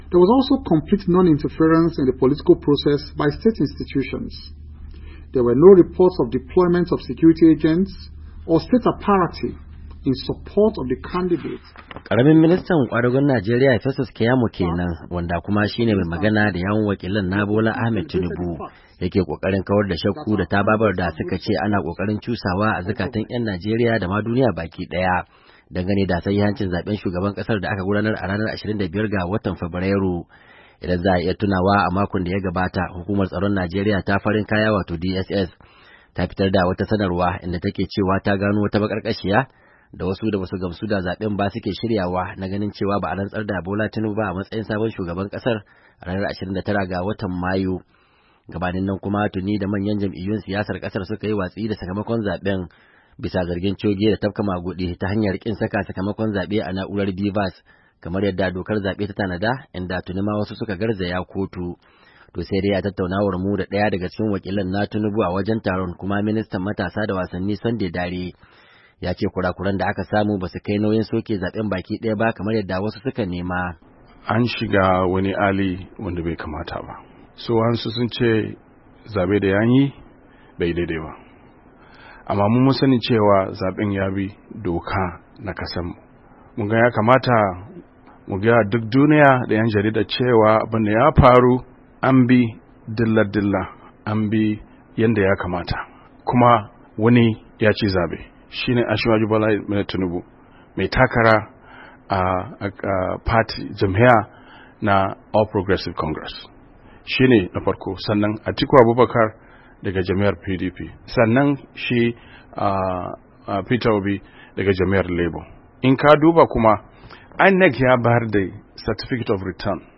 A wani taron manema labarai na kasa-da-kasa da aka gudanar a babbar cibiyar 'yan jarida da ke nan Washington DC, wakilan na Tinubu sun ce kura-kuran da aka samu a zaben, basu kai nauyin da za'a soke zaben baki daya ba.
To sai dai a tattaunawar mu da daya daga cikin wakilan na Tinubu a wajen taron, kuma ministan matasa da wasanni, Sunday Dare, ya ce kura-kuran da aka samu ba su kai nauyin soke zaben baki daya ba kamar yadda wasu suka nema.